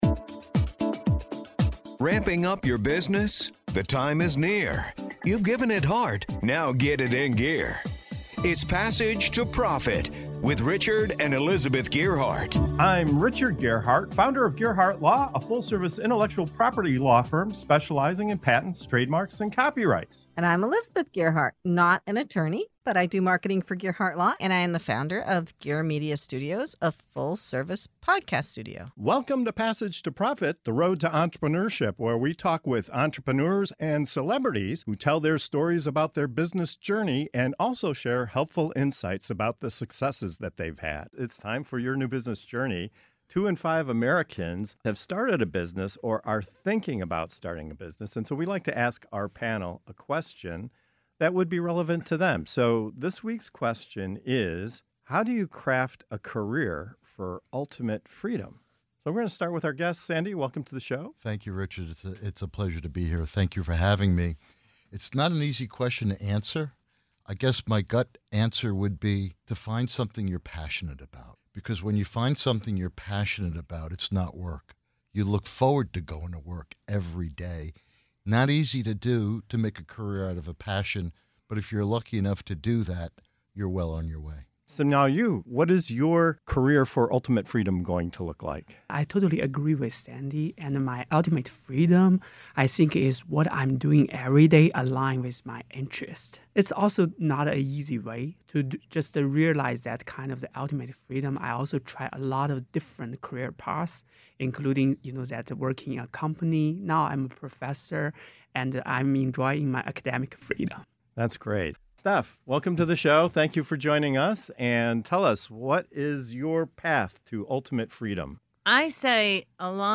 What does ultimate freedom really look like in your career? In this segment of "Your New Business Journey" on Passage to Profit Show, our panel of passionate professionals share real talk about crafting a life and business on your own terms.